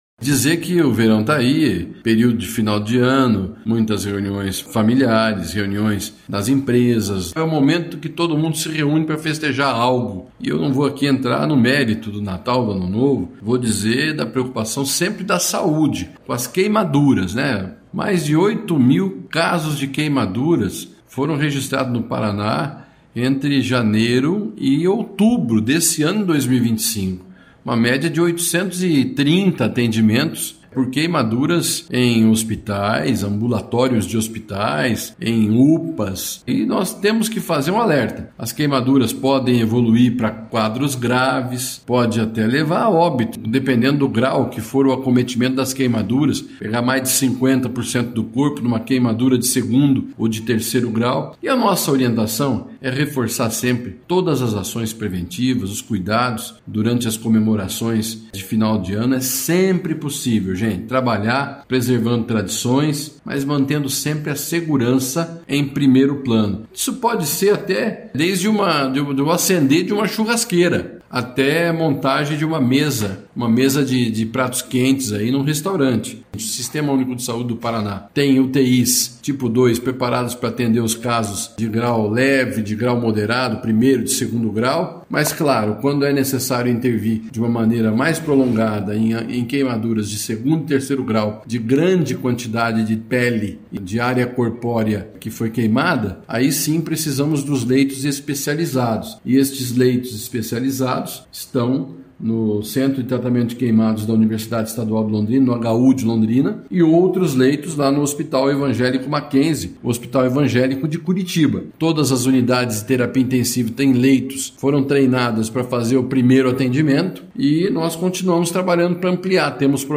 Sonora do secretário da Saúde, Beto Preto, alertando para acidentes com queimaduras neste final de ano